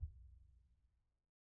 BDrumNewhit_v1_rr1_Sum.wav